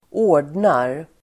Uttal: [²'å:r_dnar]